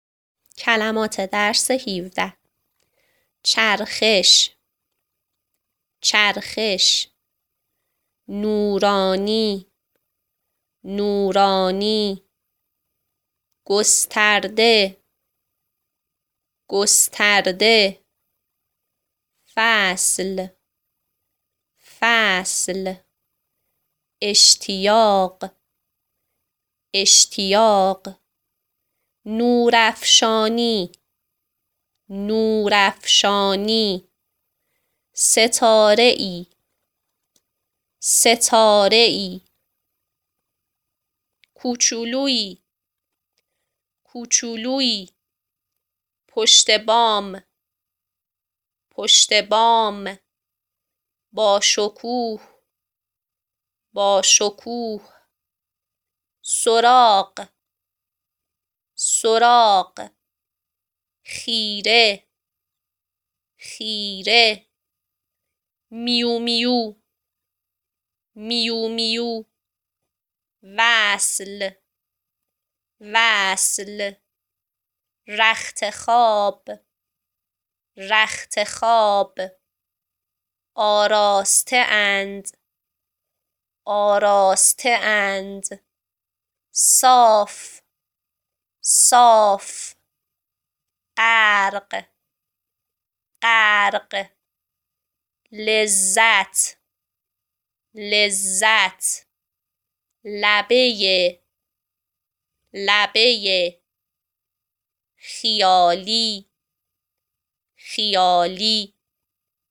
املای درس ۱۷ فارسی
دختر خوبم مثل املاهای گذشته به کلمات فایل صوتی دقت کن و آن ها را روی کاغذ بنویس ( هر کلمه دوبار تکرار شده است .)